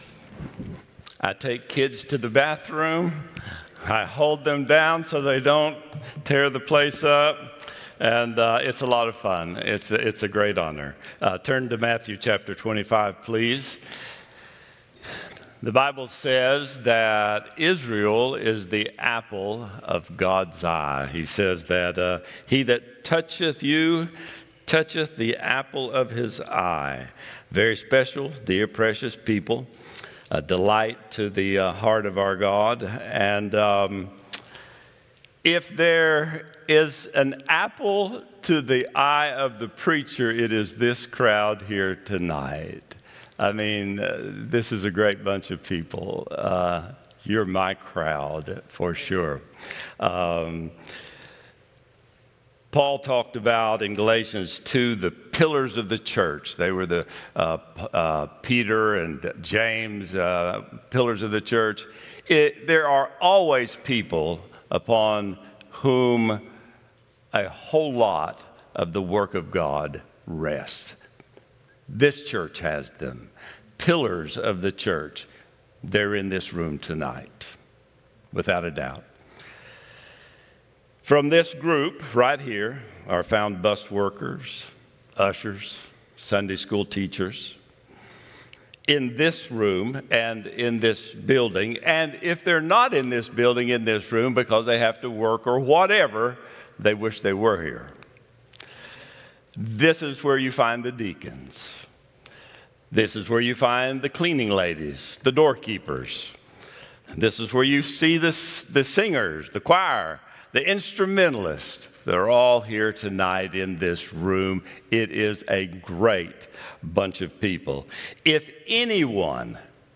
Wednesday Evening Service